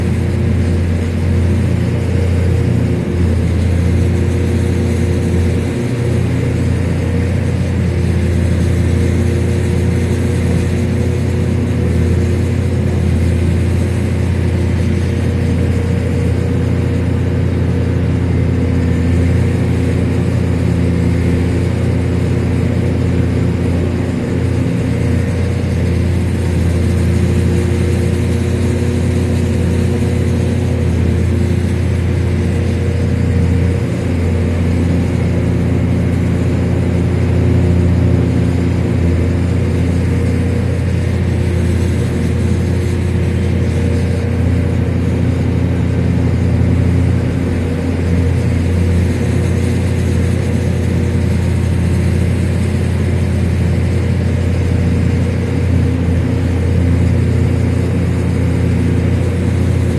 Excavator Demolishing Tower Caught on